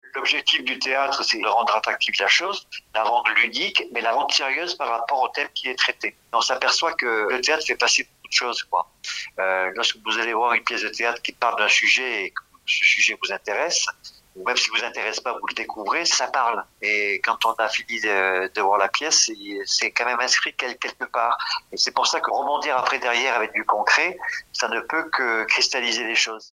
Une interview réalisée à l’occasion de leur présence sur l’événement Ta fête, organisé par Habilis le 21 mai à Barbentane, dont Bleu Tomate est partenaire.